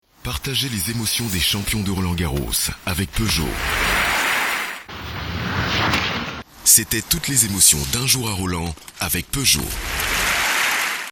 VOIX OFF, animateur radio
Sprechprobe: Sonstiges (Muttersprache):